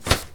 shield-hit-1.ogg